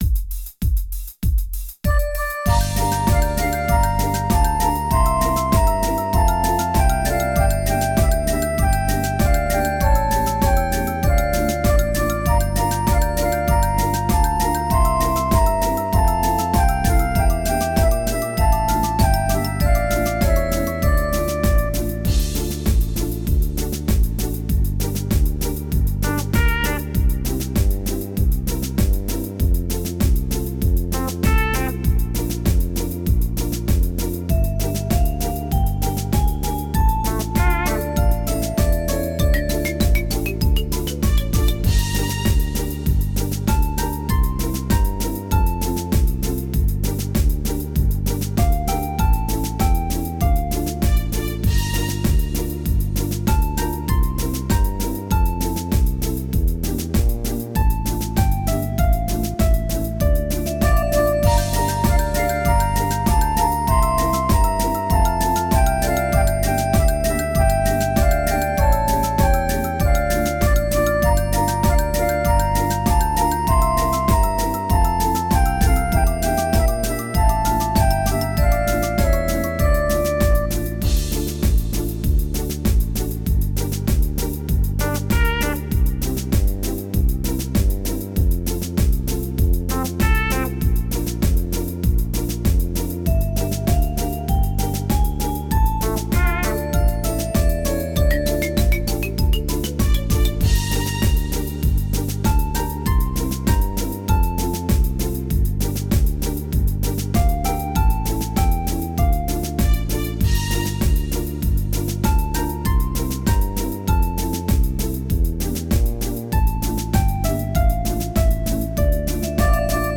Главная / Песни для детей / Песни про зиму
Слушать или скачать минус